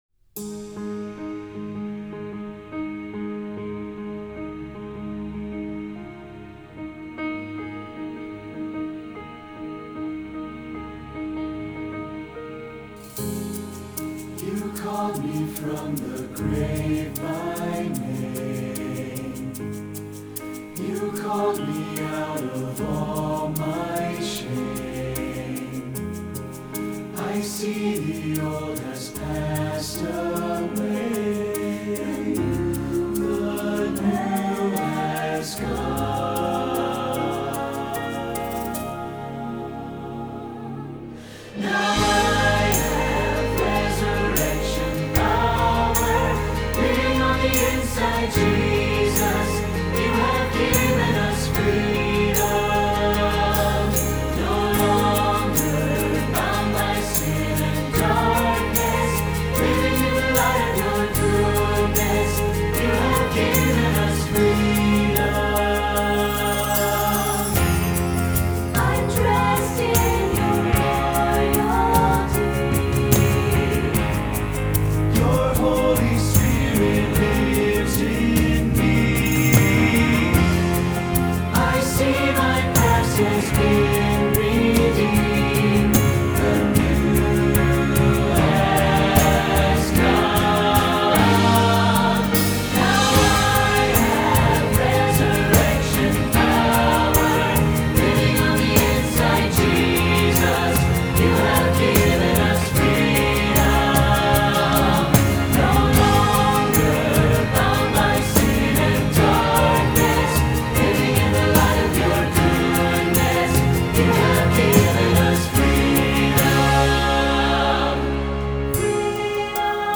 SATB
Choral Church